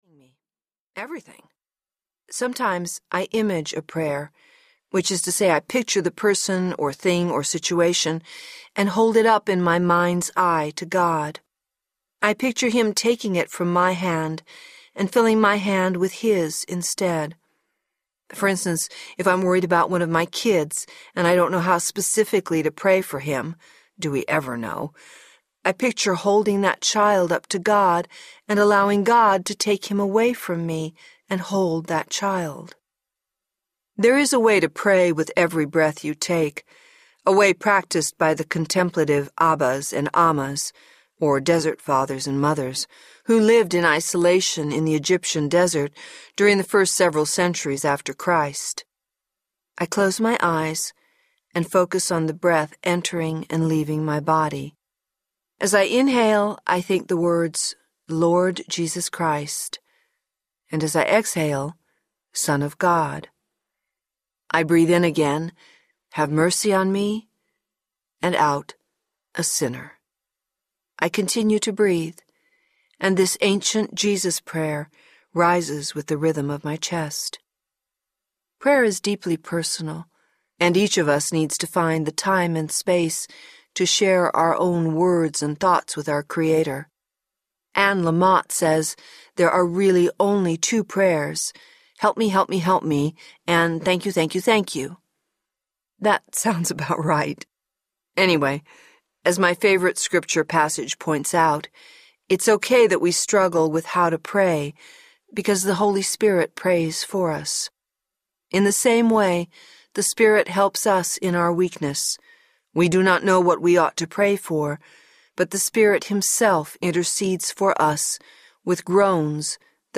A Collection of Wednesdays Audiobook